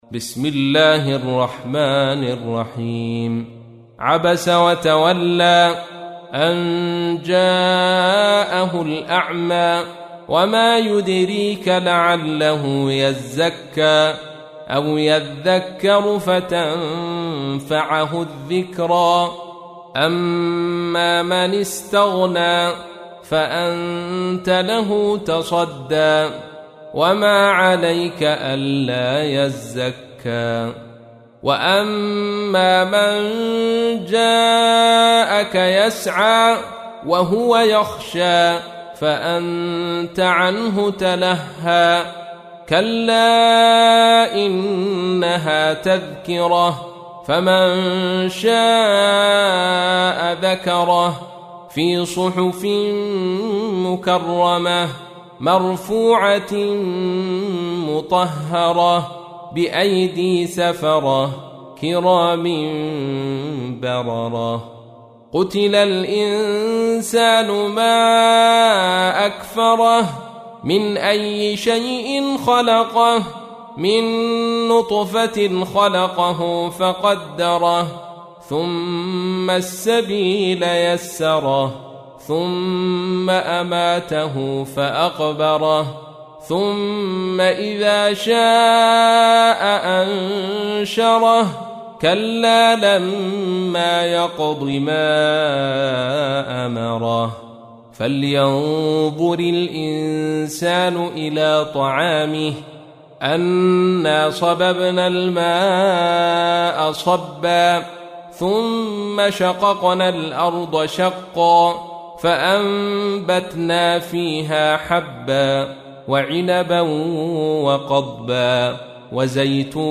تحميل : 80. سورة عبس / القارئ عبد الرشيد صوفي / القرآن الكريم / موقع يا حسين